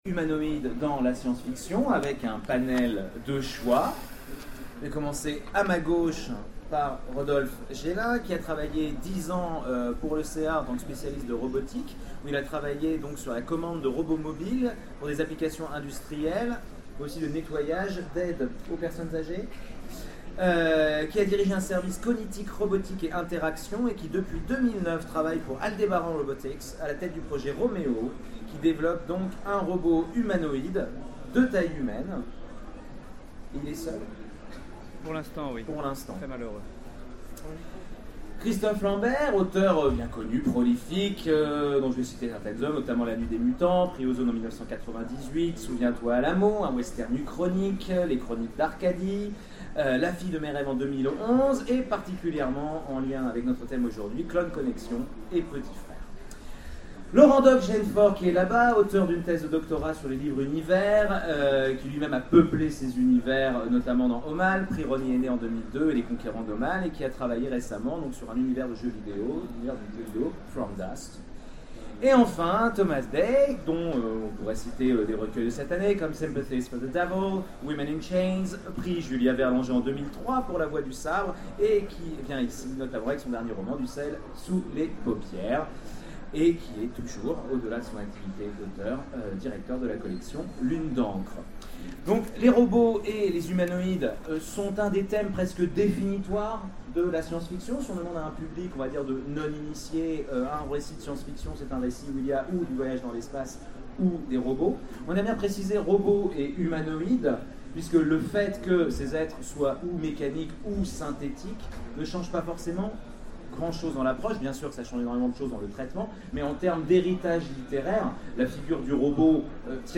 Utopiales 12 : Conférence Robots et humanoïdes dans la science-fiction